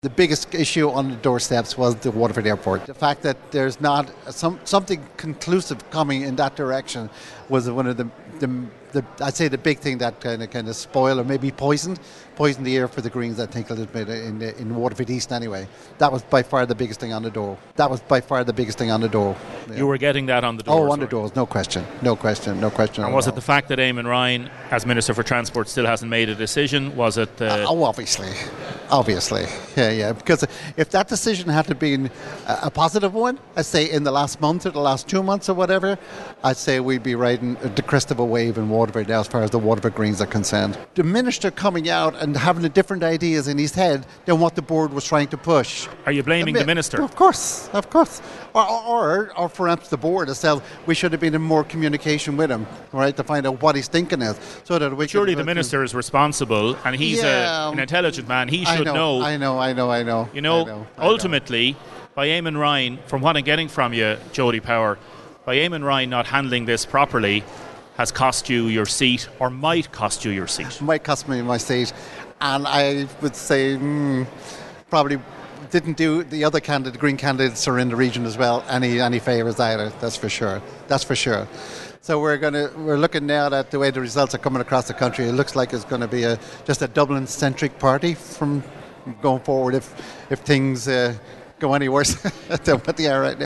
**Audio from the interview is below**